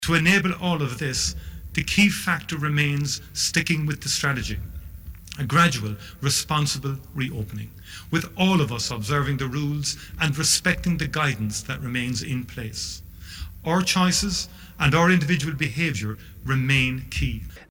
Taoiseach Micheál Martin confirmed the news in an address to the nation in the last hour.